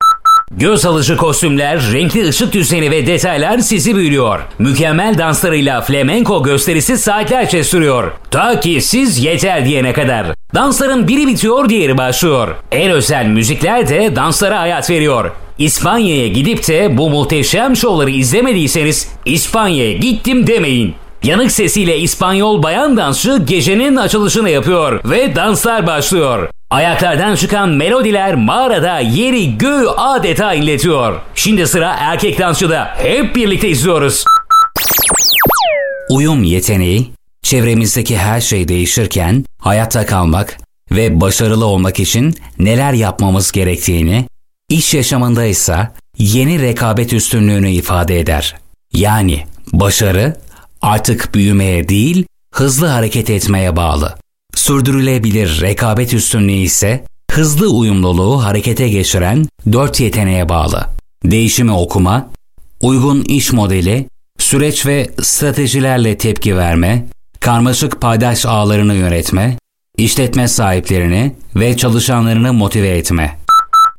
Turkish Voiceover Artist.
Sprechprobe: Industrie (Muttersprache):